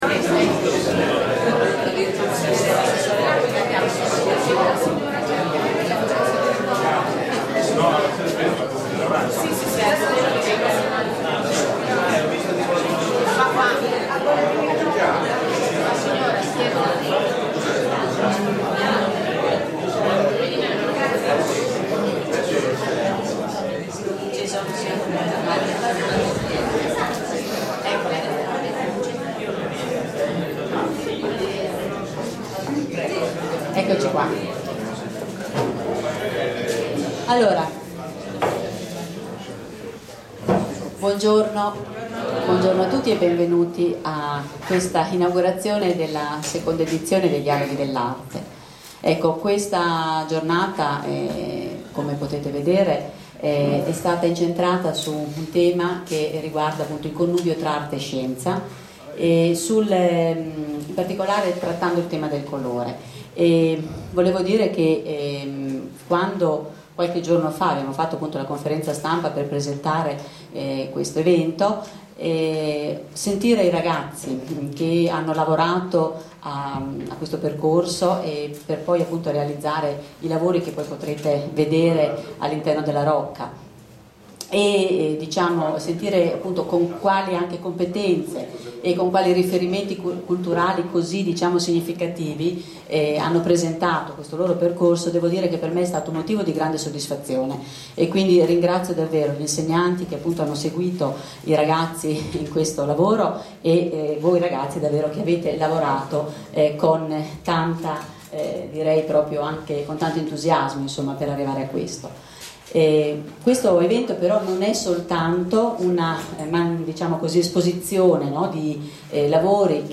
Intervento Dialoghi Dell’Arte 2011 (audio)